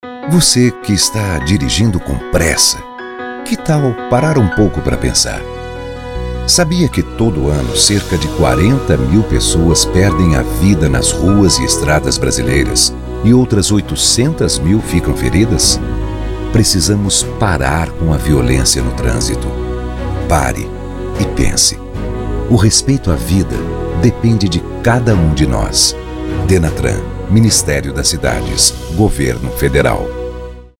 Spots de Rádio